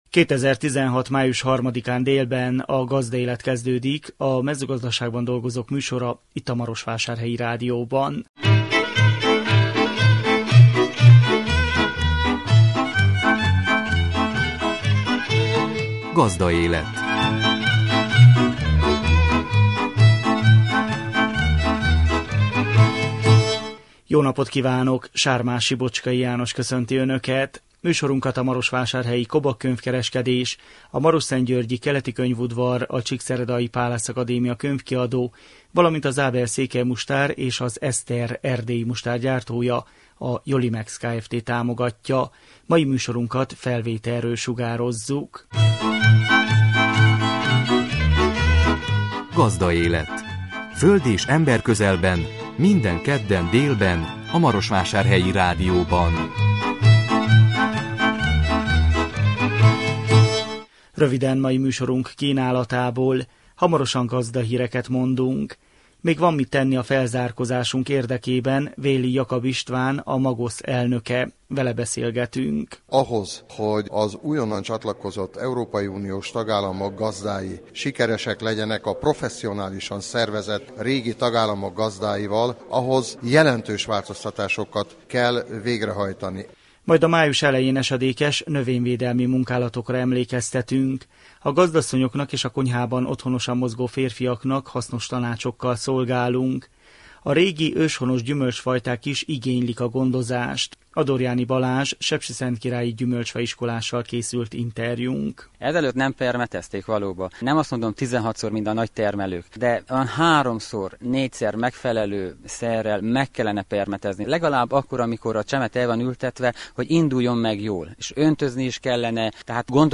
A 2016 május 3-án jelentkező műsor tartalma: Gazdahírek.
Vele beszélgetünk. Majd a május elején esedékes növényvédelmi munkálatokra emlékeztetünk.